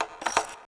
WRONGKEY.mp3